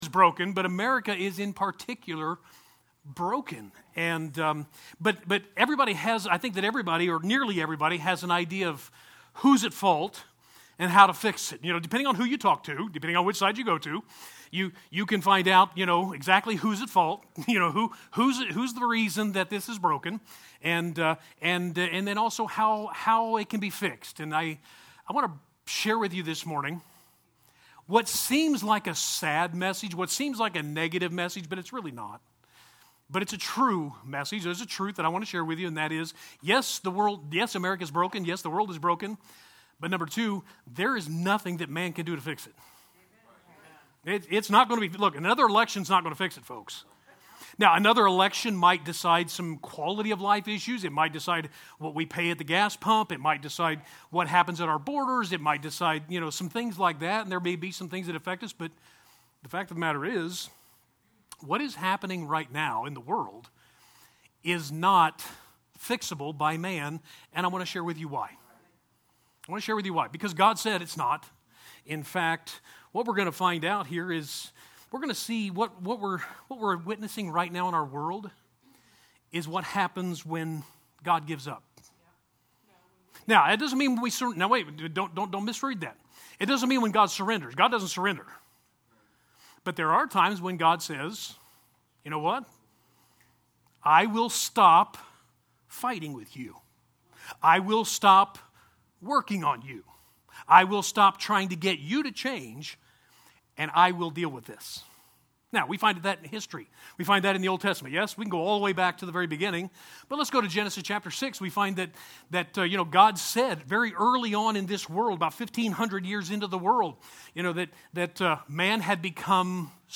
8-22-21 Sunday Message: When God Gives Up